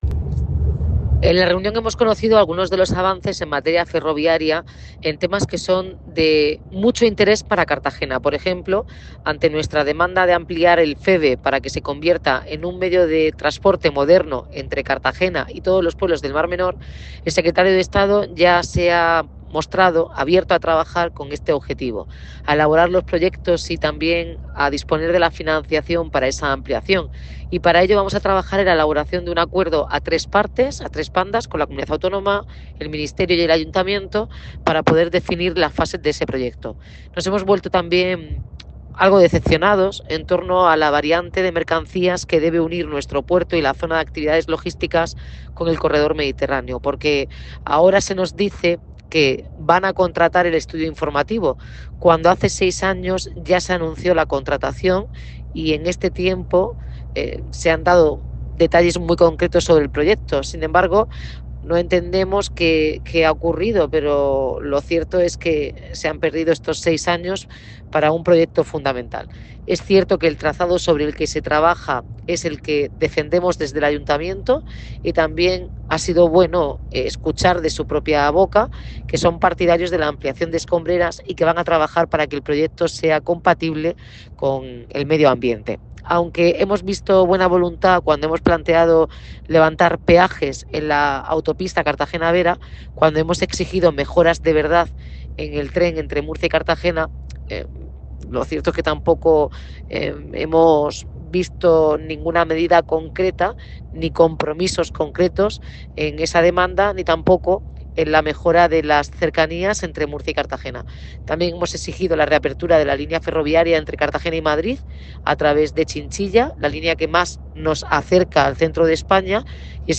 Enlace a Declaraciones de la alcaldesa Noelia Arroyo.